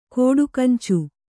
♪ kōḍu kancu